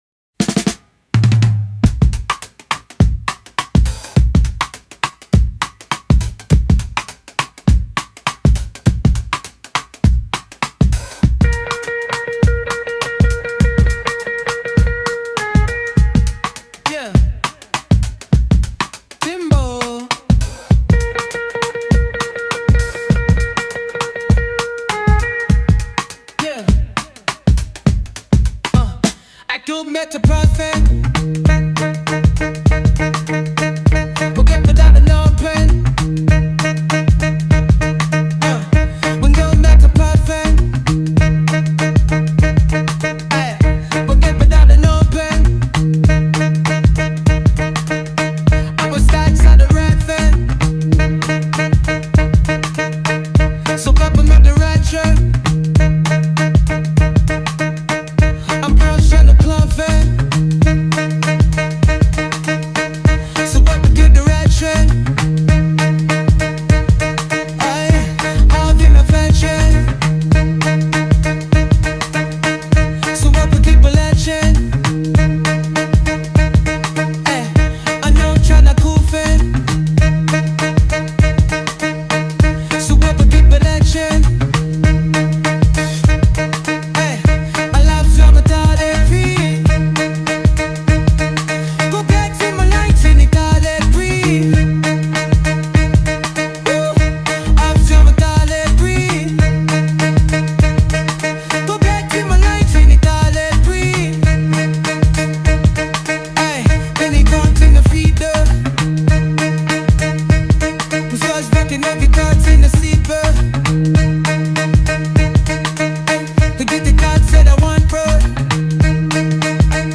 instrumental track
Afrobeats-inspired